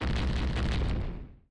050-Explosion03.opus